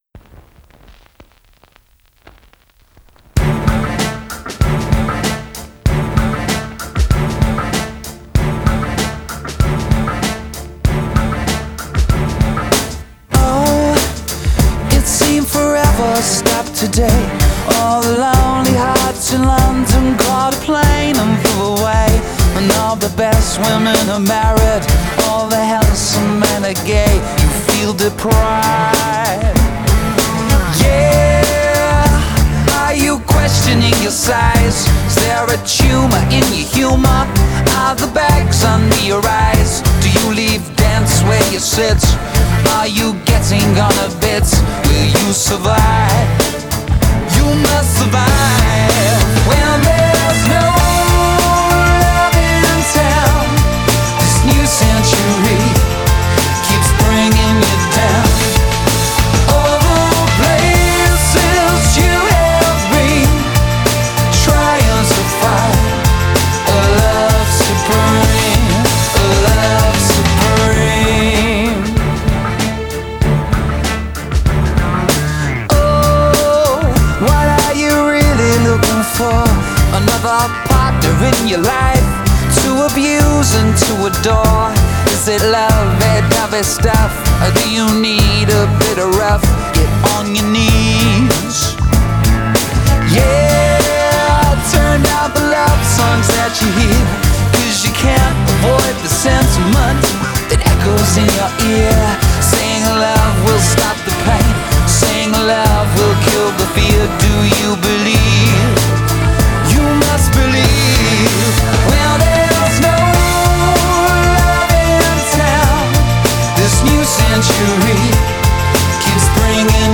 • Категория:Лучшие мировые баллады